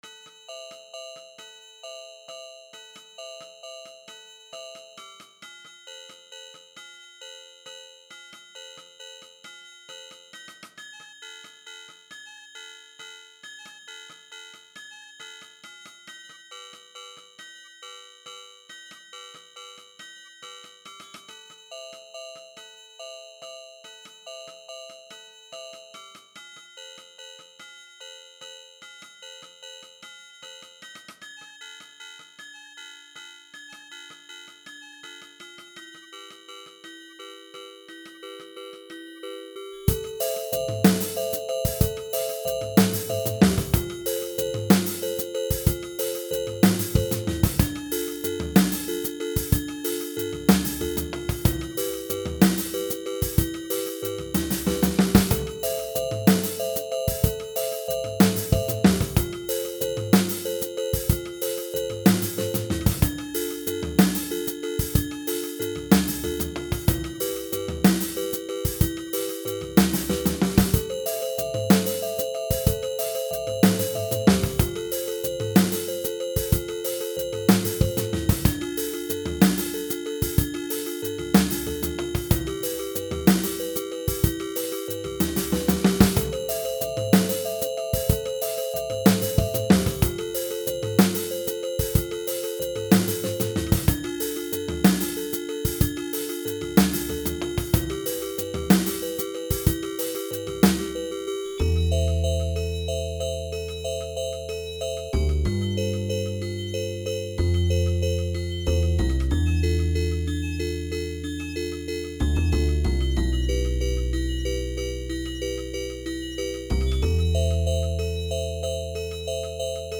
Música tranquila para escuchar en cualquier momento song #2
instrumental